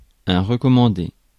Ääntäminen
Synonyymit conseillé Ääntäminen France Tuntematon aksentti: IPA: /ʁə.kɔ.mɑ̃.de/ IPA: /ʁǝ.kɔ.mɑ̃.de/ Haettu sana löytyi näillä lähdekielillä: ranska Käännöksiä ei löytynyt valitulle kohdekielelle.